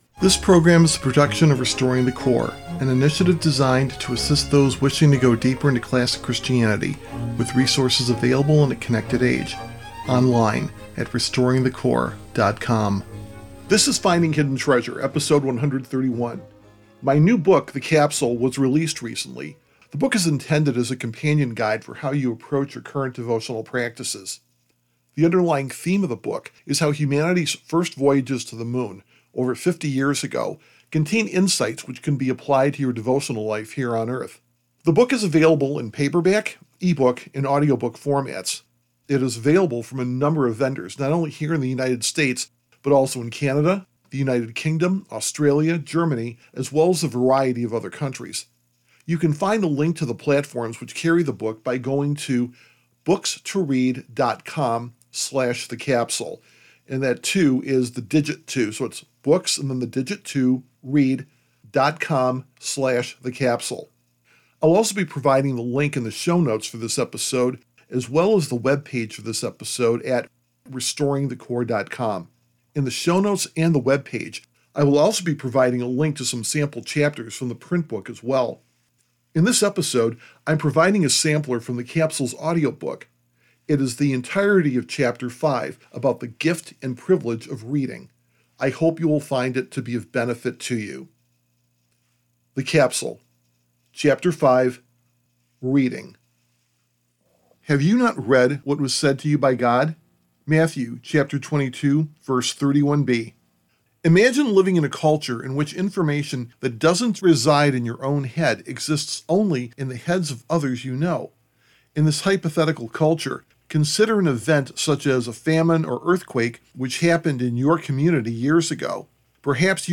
Episode 131 provides a sample chapter from the audio book version of "The Capsule". The chapter is a reflection on the gift and privilege of reading.